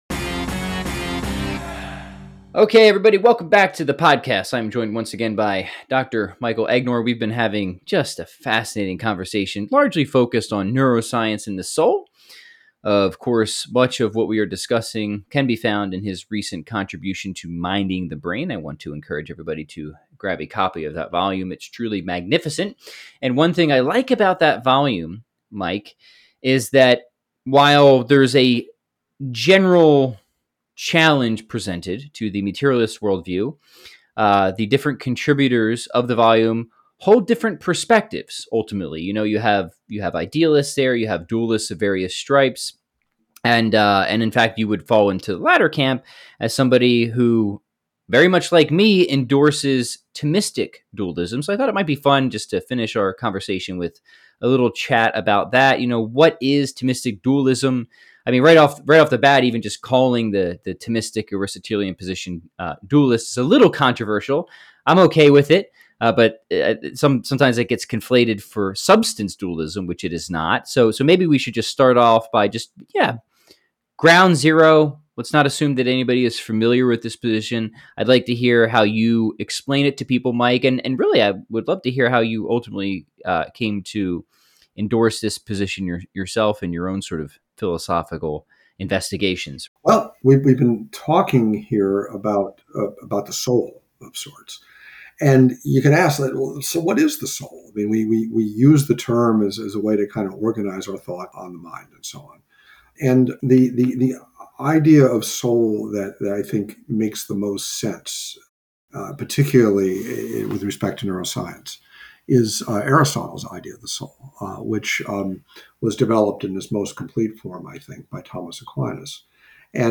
This is Part 3 of a three-part conversation.